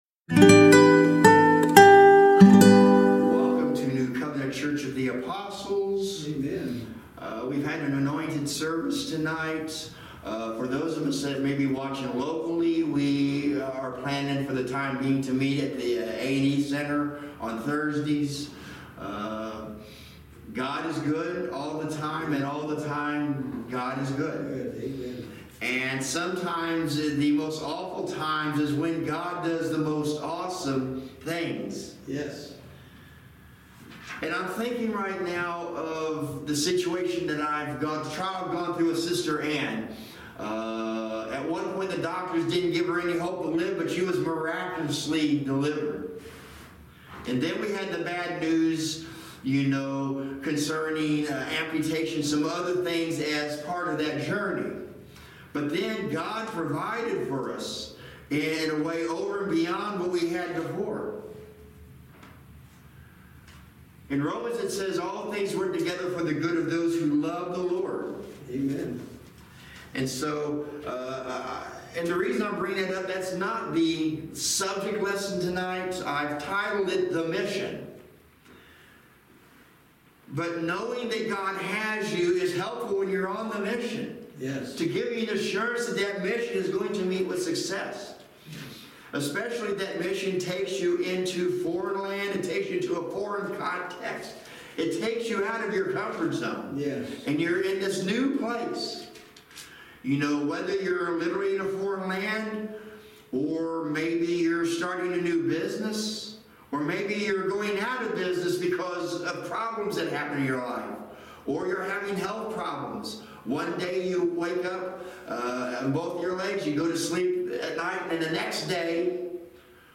Acts Passage: Acts 13:1-41 Service Type: Thirsty Thursday Midweek Teaching